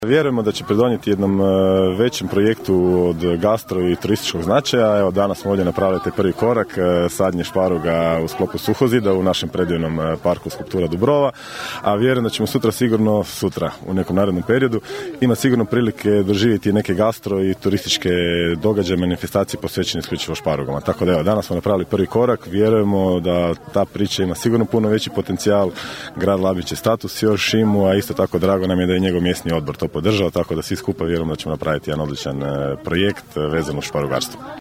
ton - Goran Vlačić), rekao je zamjenik gradonačelnika Goran Vlačić.